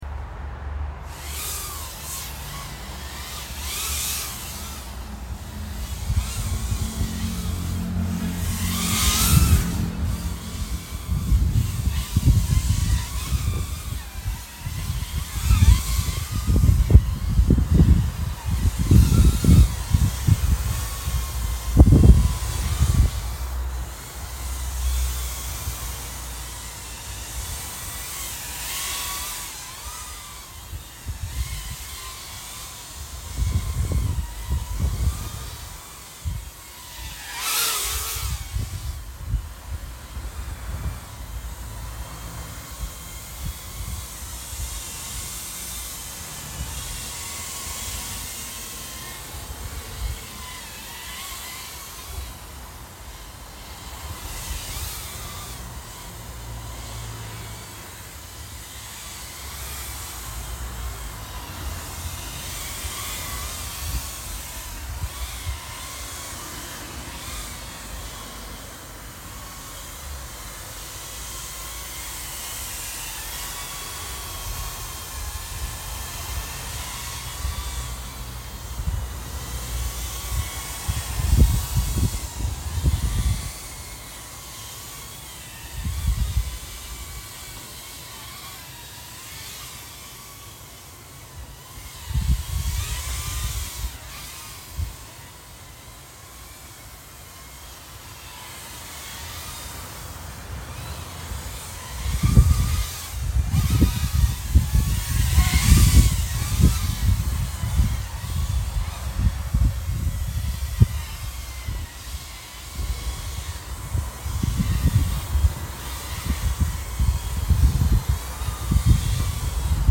"hovertest" Betafpv Meteor 75pro o4